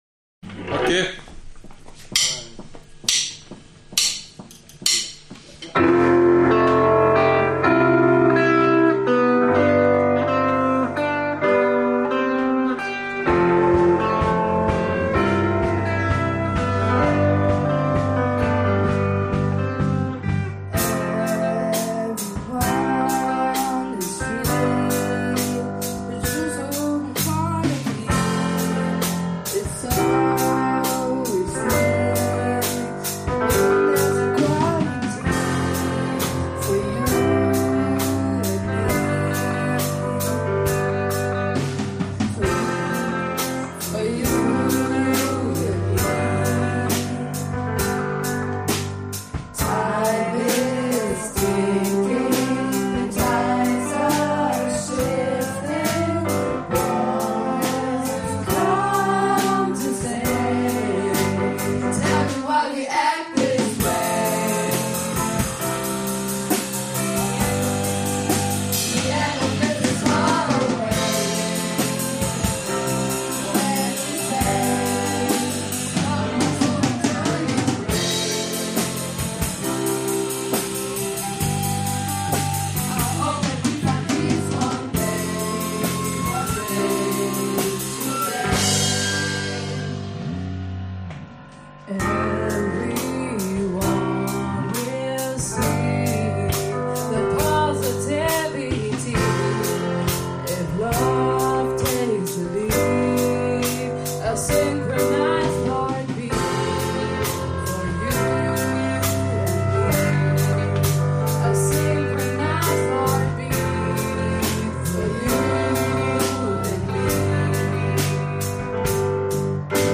Within just five hours, a powerful song was born: “One Day (Today).”